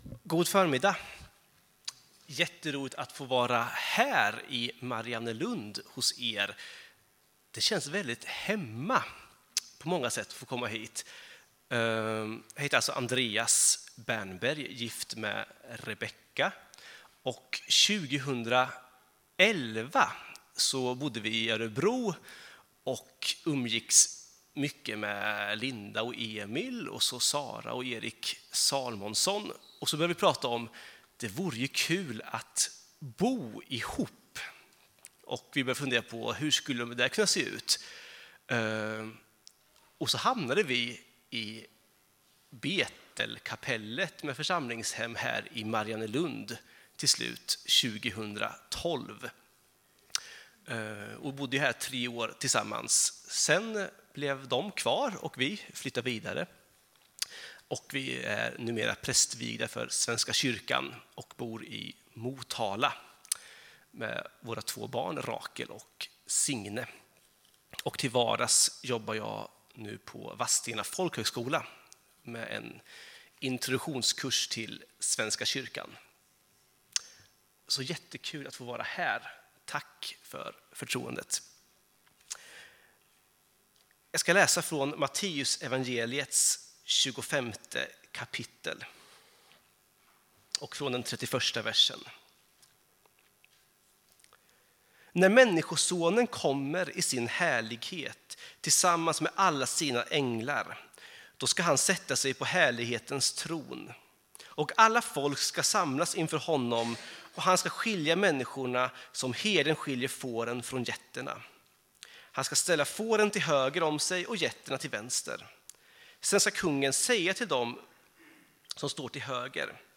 gästar oss och predikar.